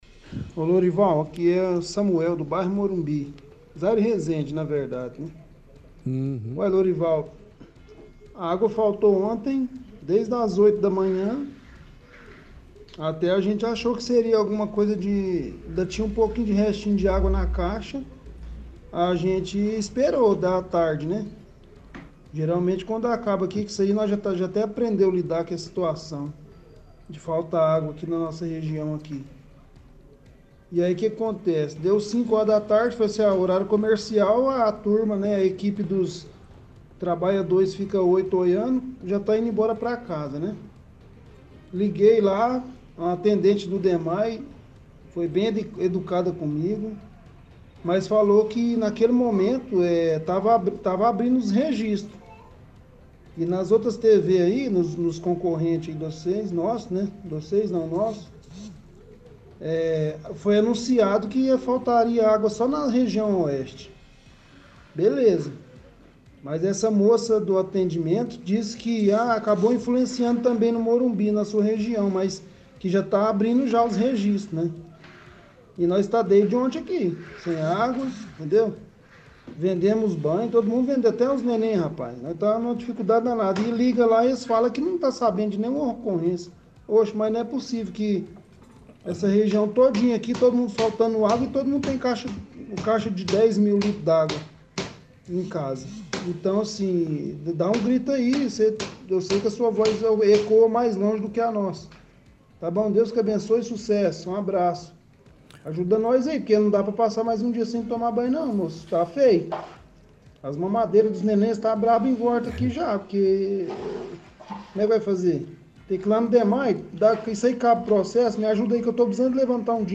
– Ouvinte reclama de falta de água no bairro Morumbi e reclama do Dmae por não dar nenhuma informação.